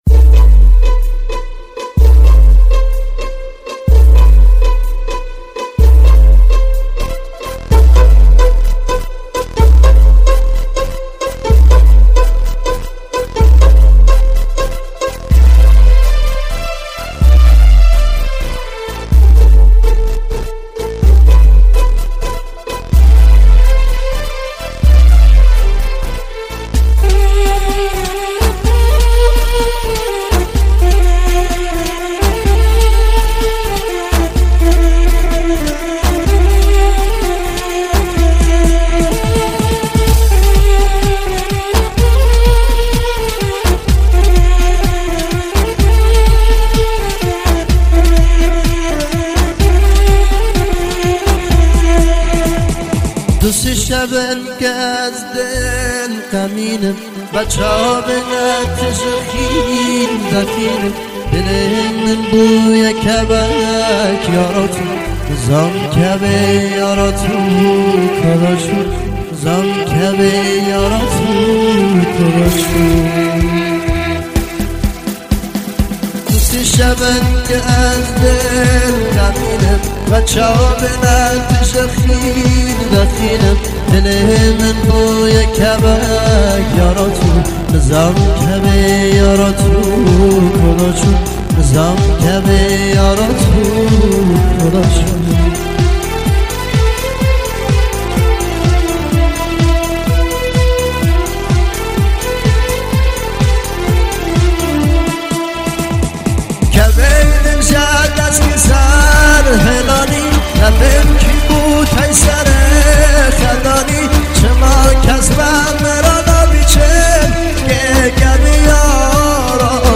آهنگ کرمانجی